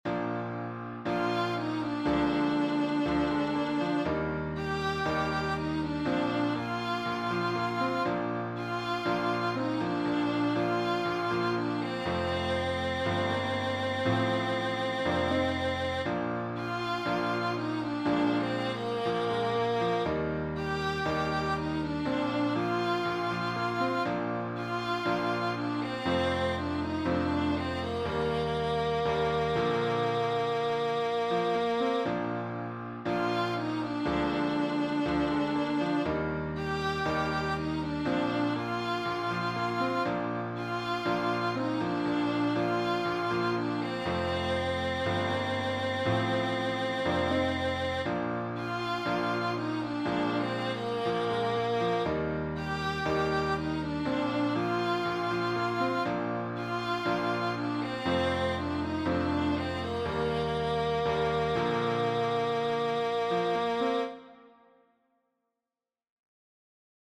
Zipper Song